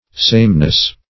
sameness - definition of sameness - synonyms, pronunciation, spelling from Free Dictionary